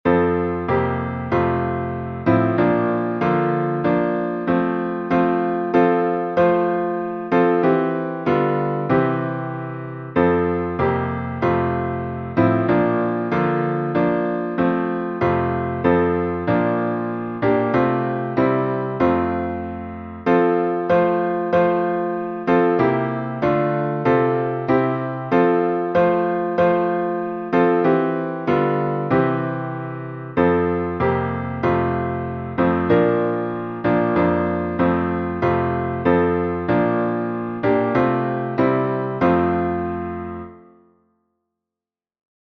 salmo_101B_instrumental.mp3